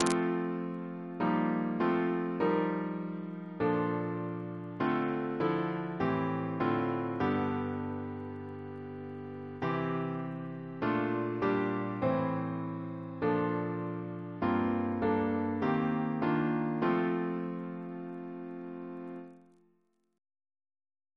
Double chant in F Composer: David Hurd (b.1950) Note: related to Hurd-F4 which is the organ accompaniment Reference psalters: ACP: 220